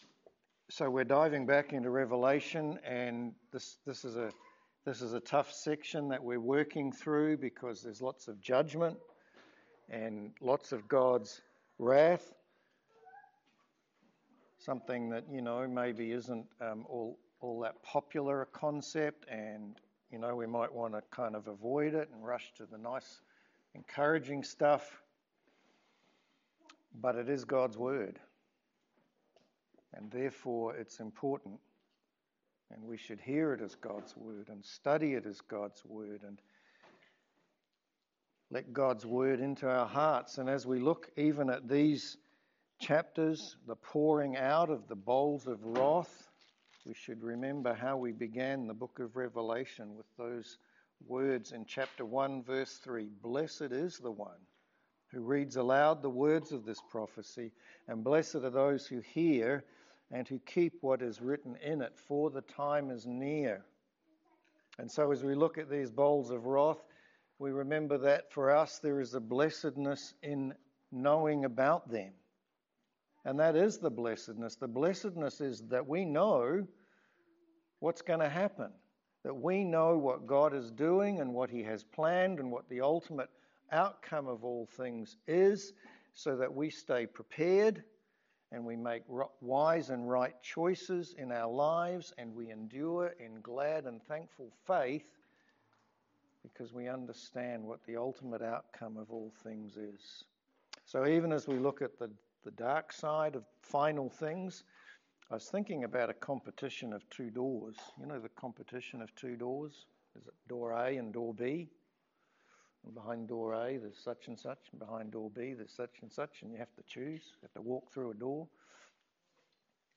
Revelation 16:10-21 Service Type: Sermon Revelation 16 teaches us to be prepared to meet Christ.